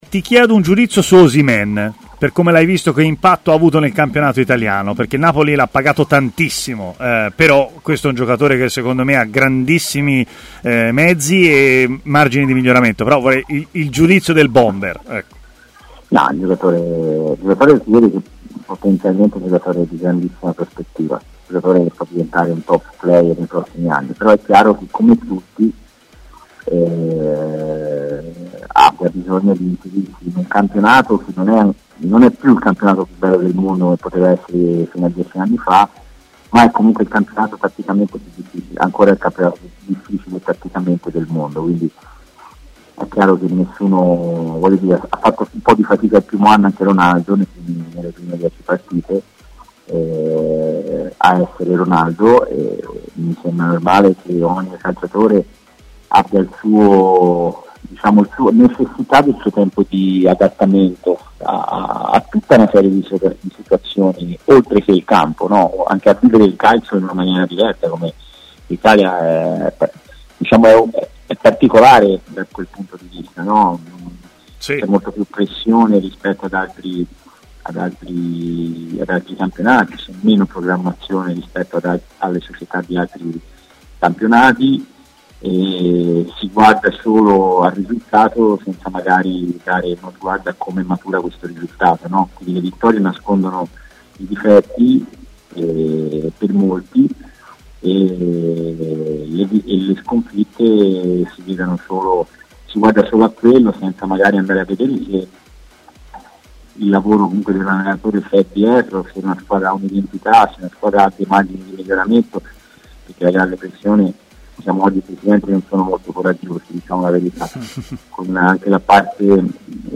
L'ex attaccante Cristiano Lucarelli, oggi allenatore della Ternana, nell'intervista a TMW Radio ha anche dato virtualmente un consiglio ad Arkadiusz Milik, attaccante del Napoli su cui, secondo molti, c'è anche la Fiorentina: "Penso che potrebbe lasciare ancora il segno in Italia.